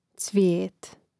cvijȇt cvijet